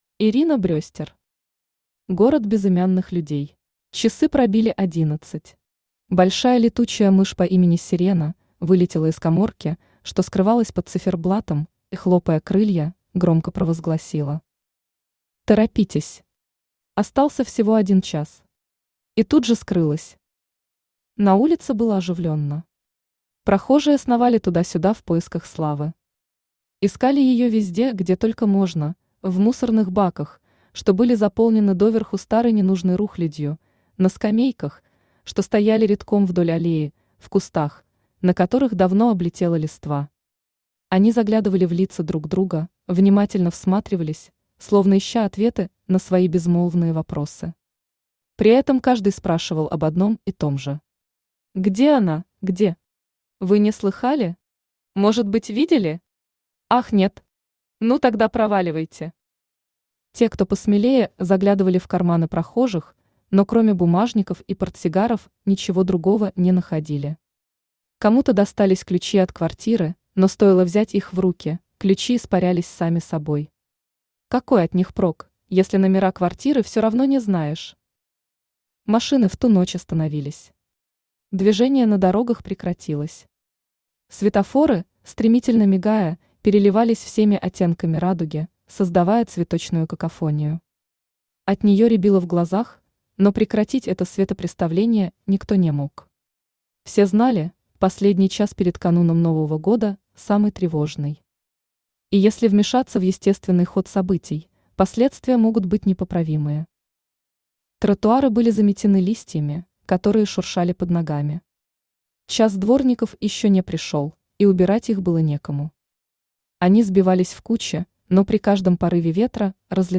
Аудиокнига Город безымянных людей | Библиотека аудиокниг
Aудиокнига Город безымянных людей Автор Ирина Брестер Читает аудиокнигу Авточтец ЛитРес.